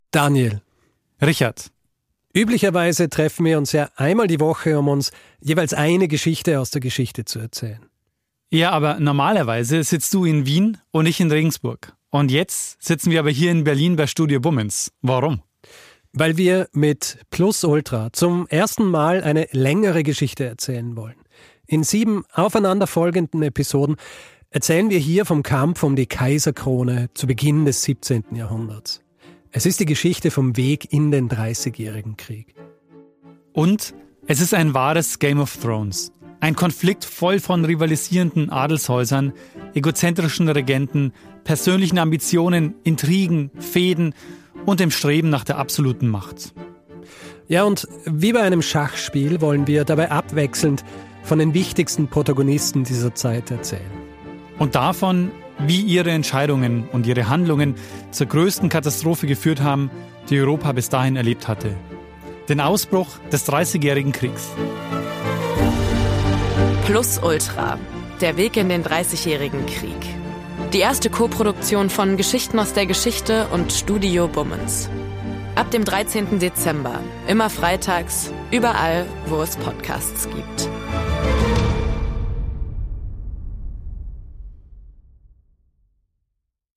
“Plus Ultra” ist ein Podcast-Drama, das gleichzeitig